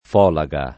[ f 0 la g a ]